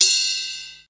• Long Tail Reverb Crash Cymbal Sound Sample C Key 12.wav
Royality free crash single shot tuned to the C note.
long-tail-reverb-crash-cymbal-sound-sample-c-key-12-q60.wav